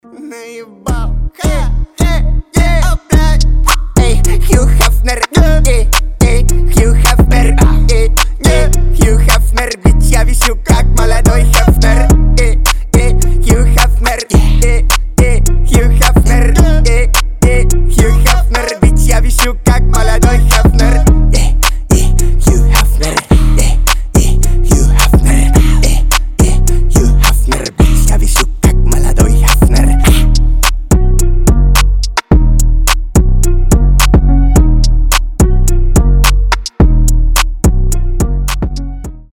Русские рингтоны
басы
качающие